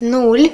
Added Ukrainian numbers voice files